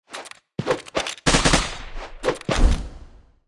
Media:Sfx_Anim_Super_Heavy.wavMedia:Sfx_Anim_Ultra_Heavy.wav 动作音效 anim 在广场点击初级、经典、高手和顶尖形态或者查看其技能时触发动作的音效
Sfx_Anim_Super_Heavy.wav